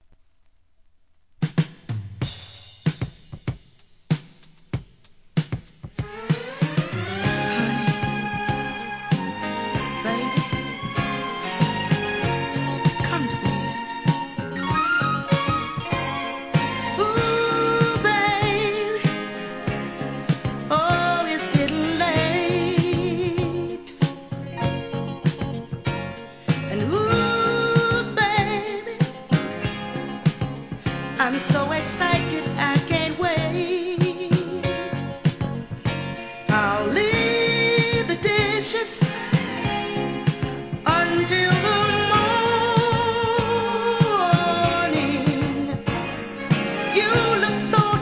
SOUL/FUNK/etc...
人気メロ〜